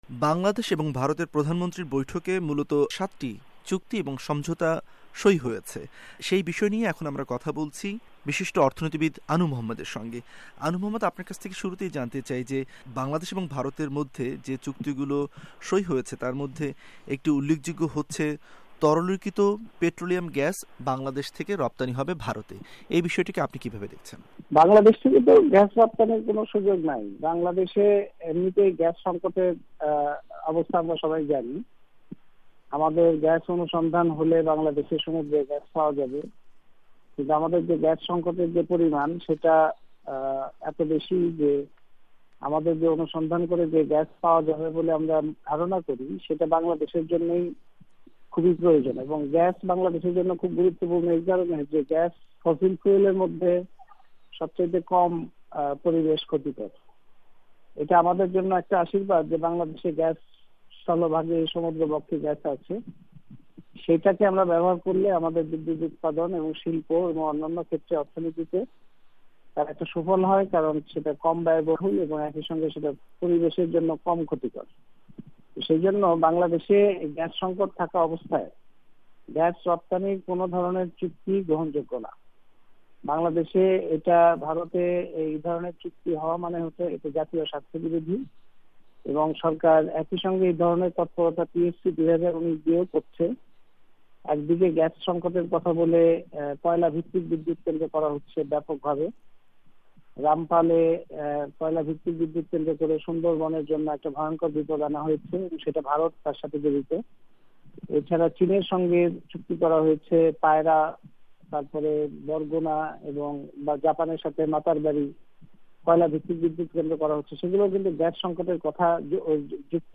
বাংলাদেশের তেল-গ্যাস-খনিজ সম্পদ ও বিদ্যুৎ-বন্দর রক্ষা জাতীয় কমিটির সদস্যসচিব এবং বিশিষ্ট অর্থনীতিবিদ অধ্যাপক আনু মুহাম্মদ বলেছেন, প্রধানমন্ত্রী শেখ হাসিনার ভারত সফরে বাংলাদেশের জন্য উল্লেখযোগ্য তেমন কোন অর্জন হয়নি। ভয়েস অফ অআমেরিকার ওয়াশিংটন স্টুডিও